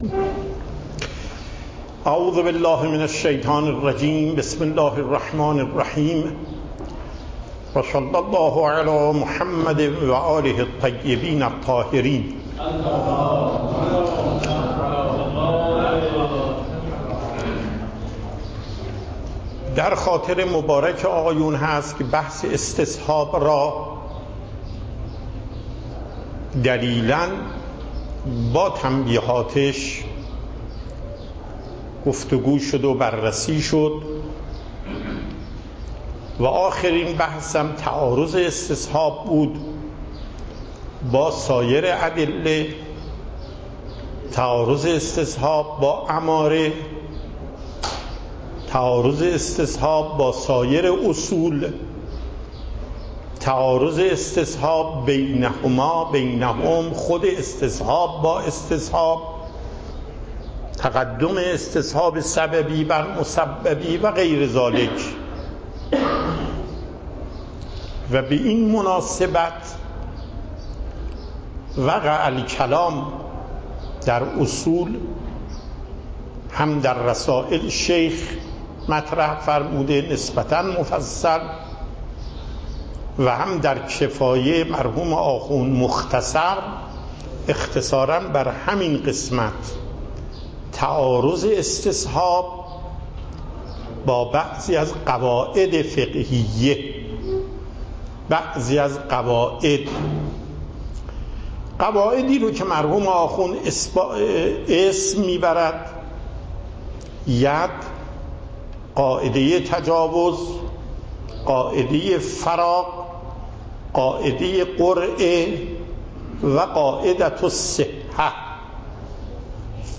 صوت و تقریر درس پخش صوت درس: متن تقریر درس: ↓↓↓ تقریری ثبت نشده است.
درس اصول آیت الله محقق داماد